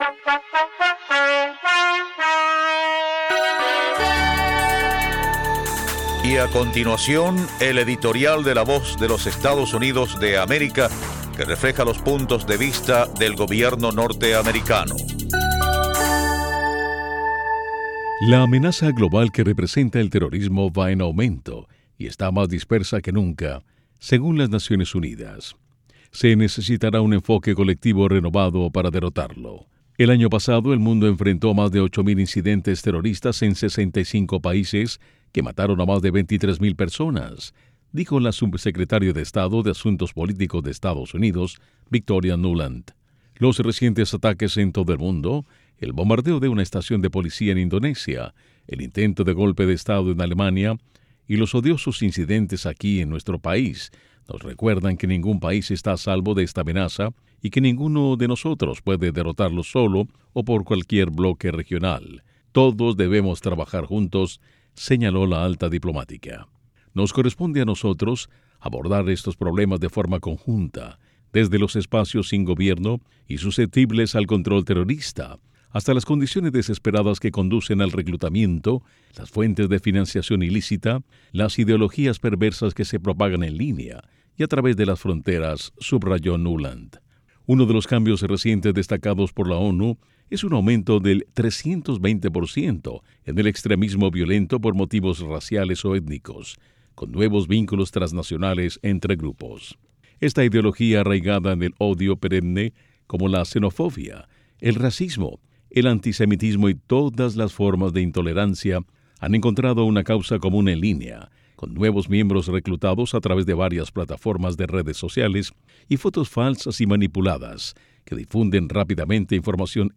A continuación un editorial que refleja la opinión del gobierno de Estados Unidos: La amenaza global que representa el terrorismo va en aumento y está más dispersa que nunca, según las Naciones Unidas. Se necesitará un enfoque colectivo renovado para derrotarlo.